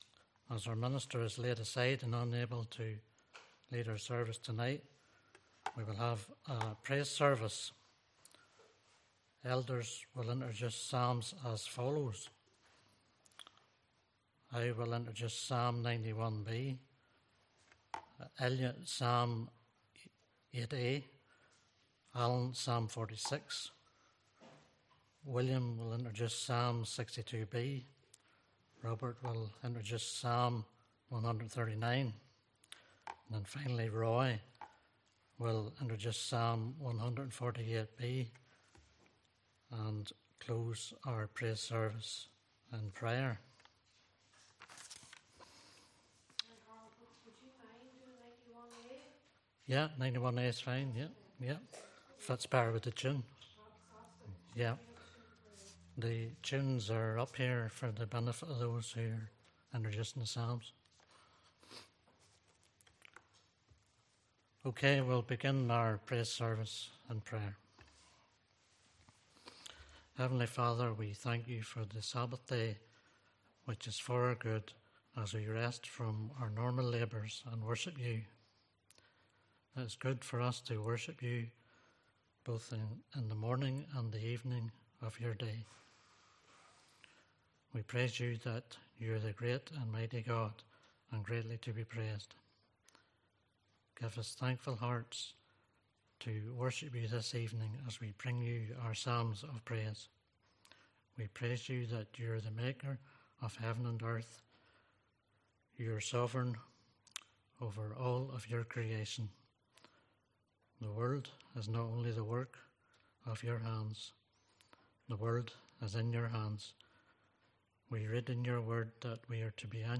Praise Service March